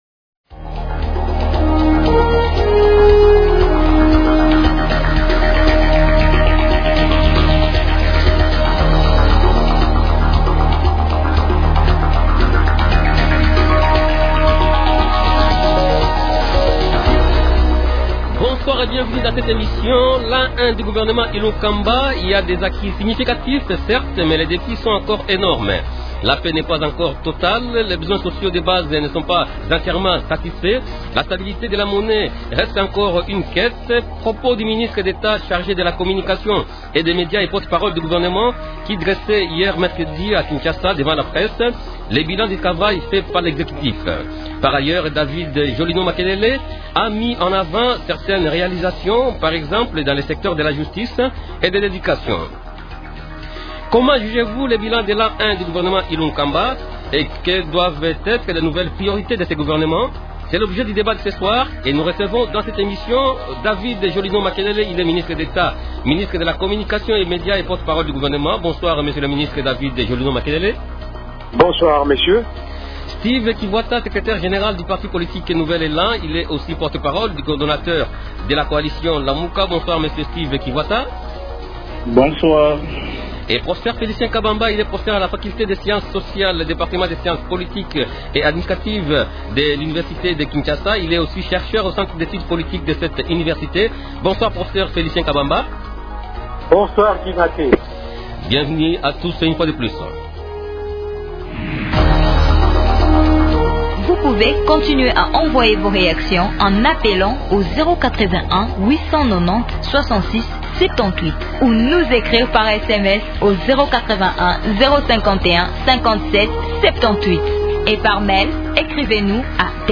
Propos du ministre d’Etat, chargé de la Communication et des Médias et porte-parole du gouvernement qui dressait hier mercredi à Kinshasa le bilan du travail fait par l’exécutif devant la presse.